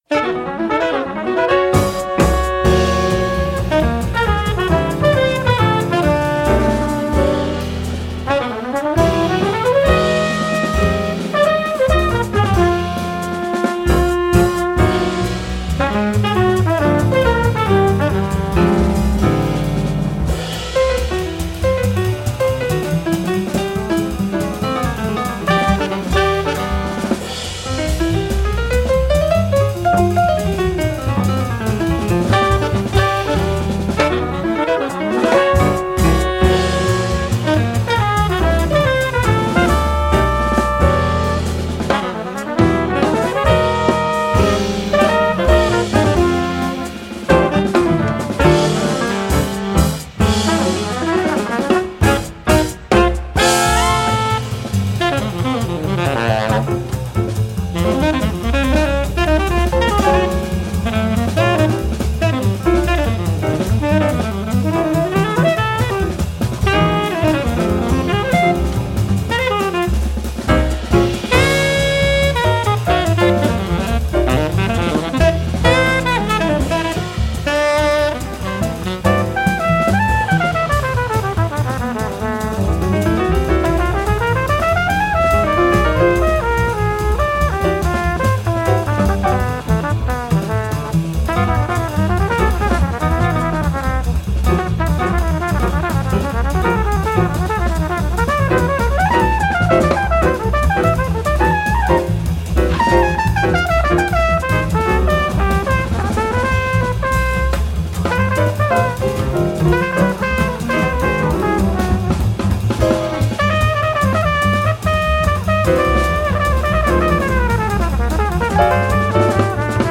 Interviews and Live Sessions